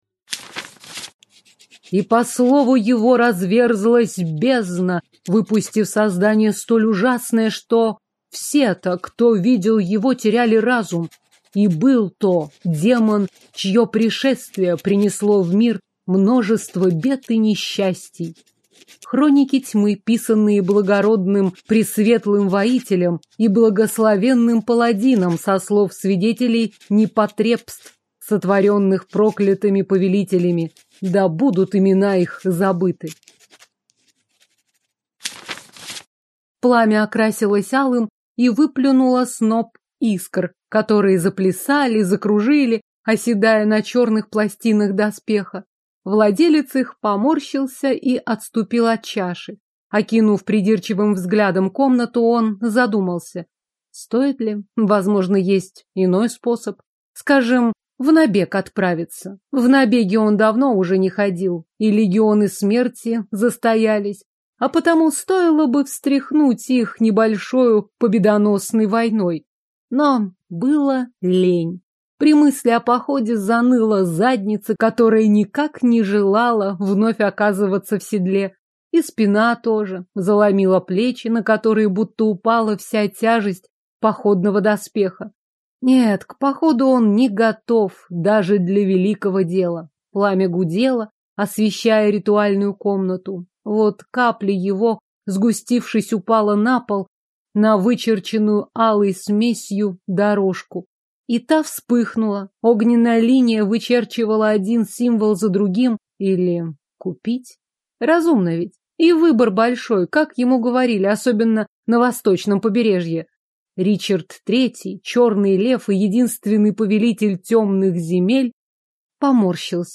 Аудиокнига Пять невест и одна демоница | Библиотека аудиокниг